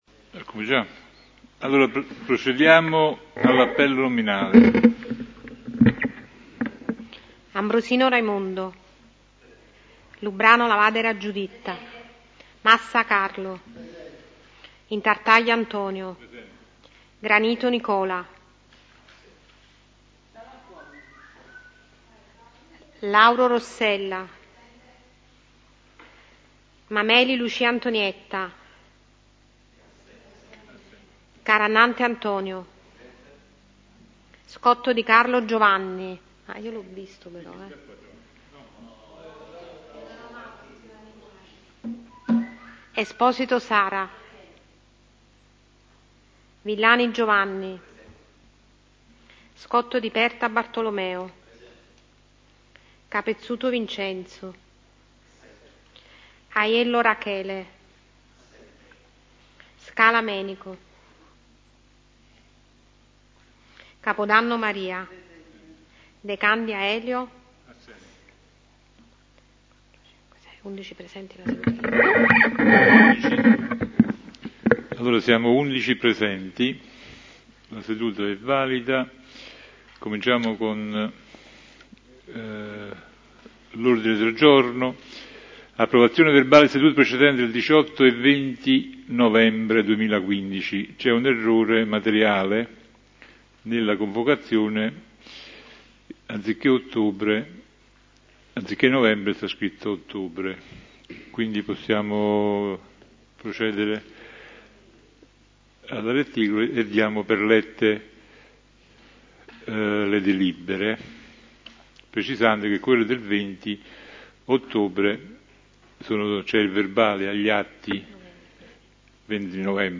Pubblicato nella RADIO il Consiglio Comunale del 30 novembre 2015